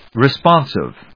音節re・spon・sive 発音記号・読み方
/rɪspάnsɪv(米国英語), rɪˈspɑ:nsɪv(英国英語)/